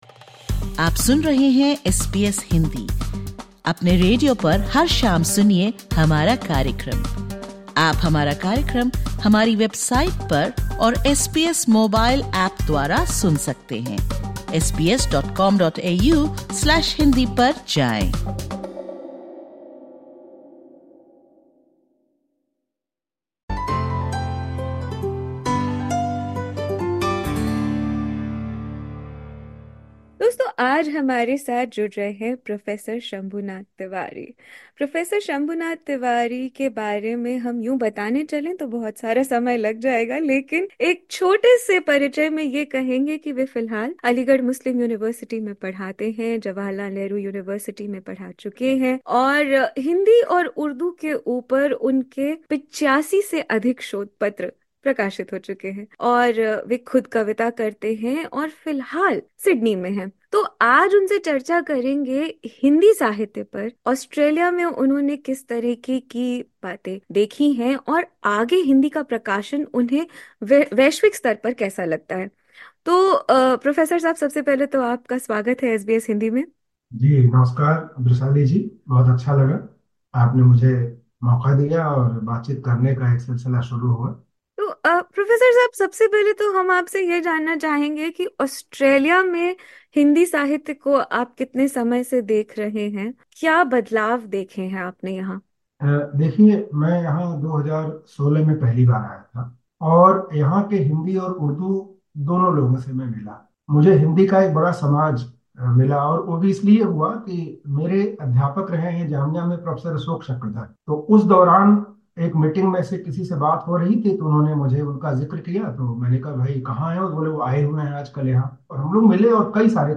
इस साक्षात्कार में उन्होंने ऑस्ट्रेलिया के कई लेखकों की चर्चा की, और बताया कि किस तरह आधुनिक हिंदी साहित्य गंगा-जमुनी तहज़ीब का सबसे अच्छा उदाहरण है। साथ ही उन्होंने बताया कि कैसे प्रवासी लेखक भारत में प्रकाशित हो सकते हैं।